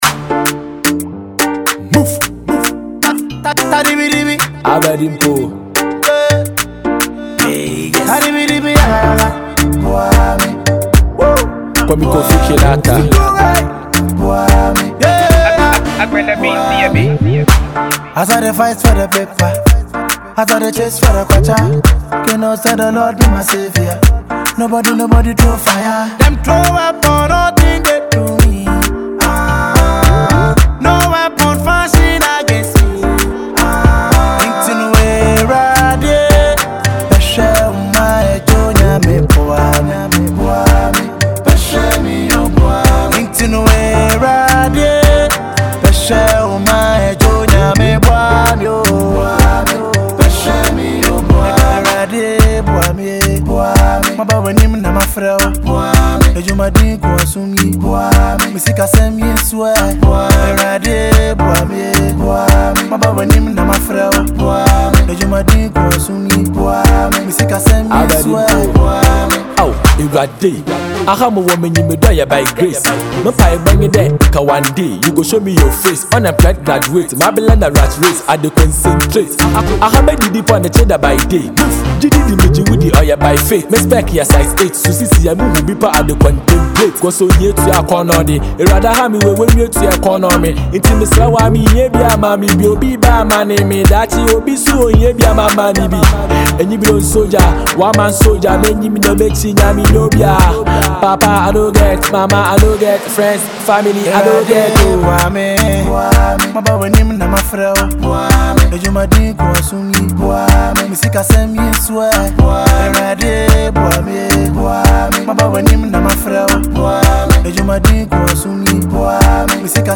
multiple award-winning highlife singer